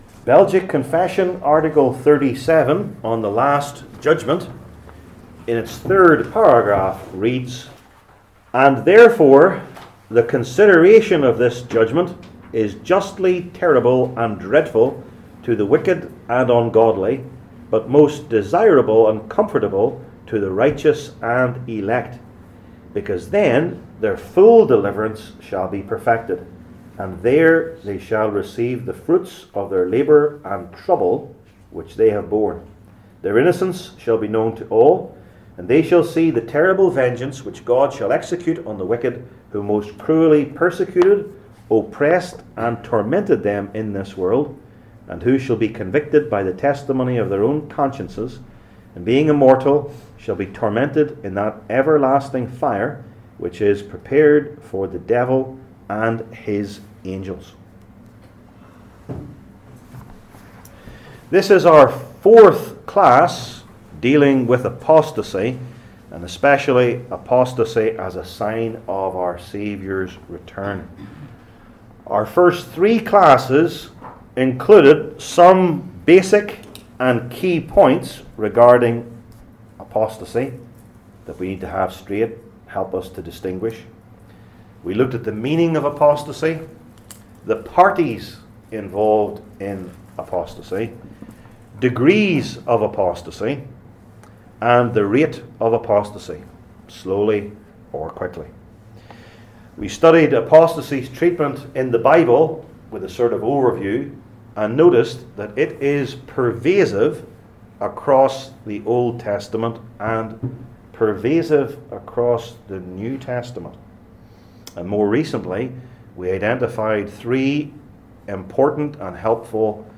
I Timothy 6:6-21 Service Type: Belgic Confession Classes THE LAST JUDGMENT …